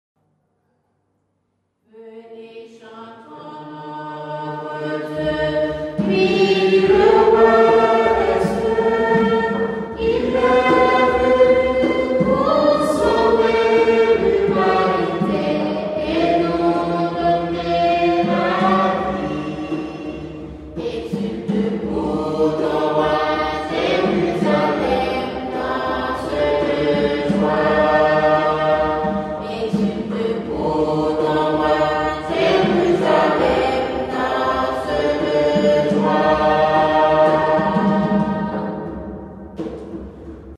Digital recording, at 48MHz in the studio or on-site, stereo or multitrack - See how it works
Voices of St. Marie-Madelaine
Duchity, Haiti